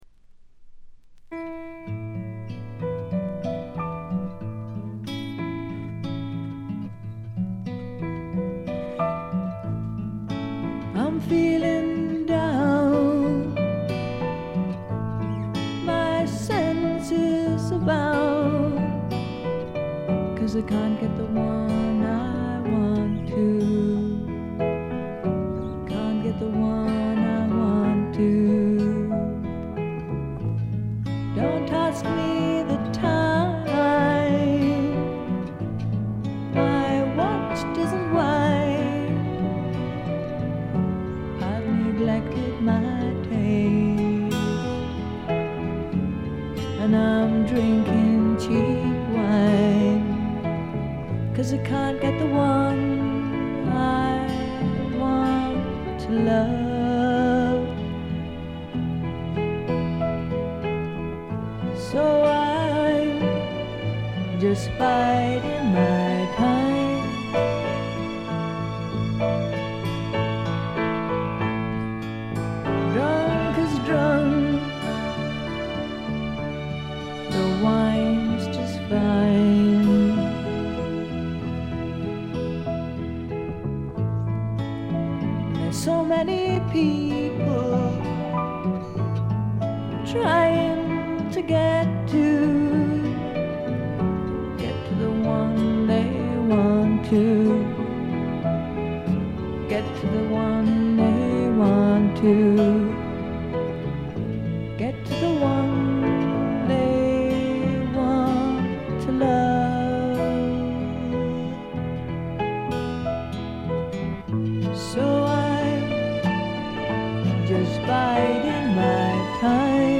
ごくわずかなノイズ感のみ。
音の方はウッドストック・サウンドに英国的な香りが漂ってくるという、この筋の方にはたまらないものに仕上がっています。
試聴曲は現品からの取り込み音源です。